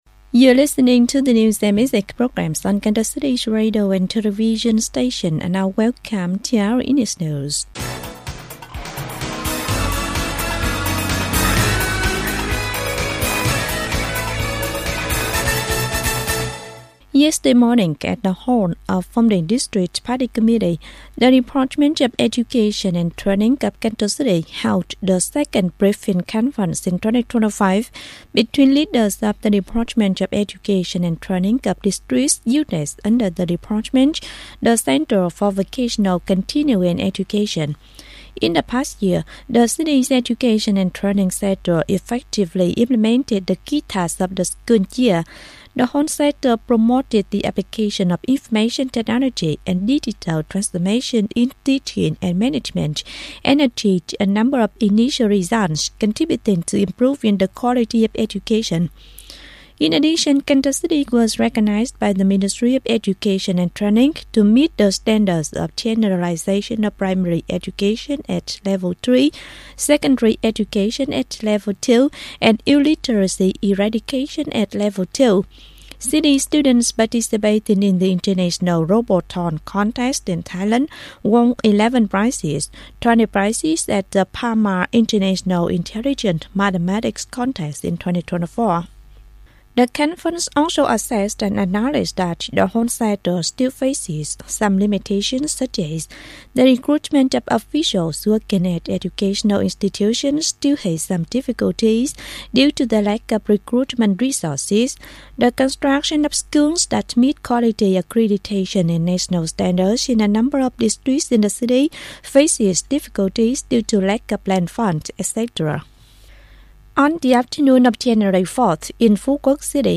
Bản tin tiếng Anh 5/1/2025